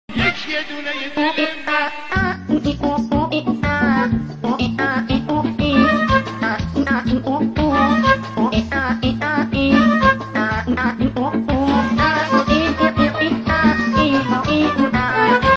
ریمیکس با صدای گربه